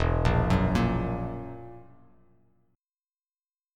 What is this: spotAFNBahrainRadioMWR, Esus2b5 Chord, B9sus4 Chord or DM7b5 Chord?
Esus2b5 Chord